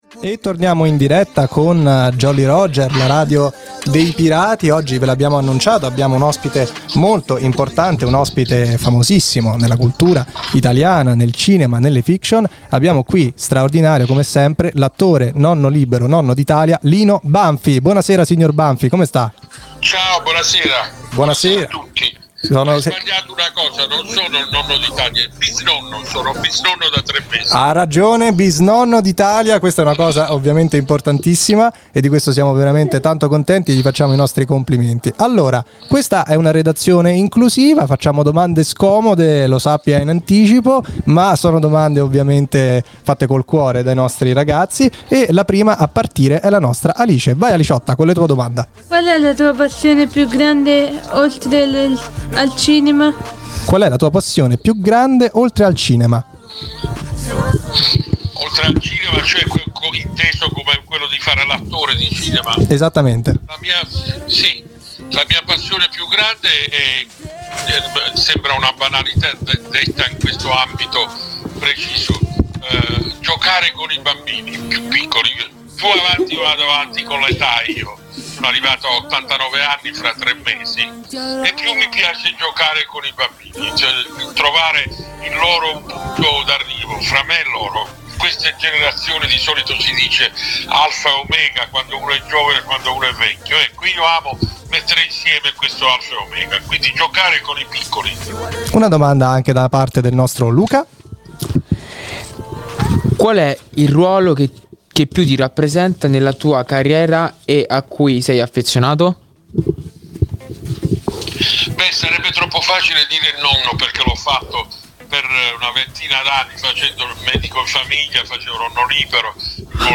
Jolly Roger - Puntata 19 - Intervista a Lino Banfi
Il (bis)nonno d’Italia si è raccontato con ironia e dolcezza ai giovani speaker della Radio dei Pirati.